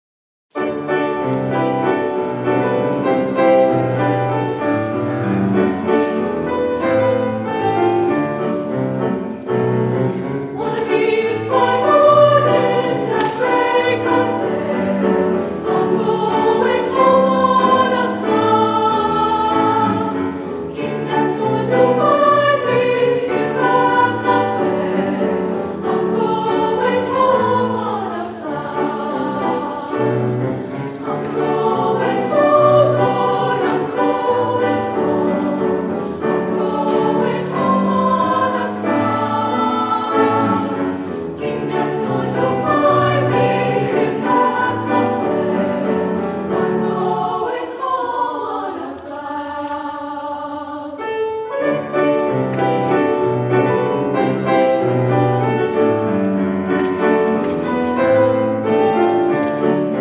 annual winter concert
a mixture of classical and holiday music